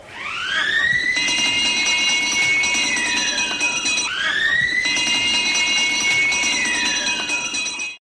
Las sirenas de Tiempo de Juego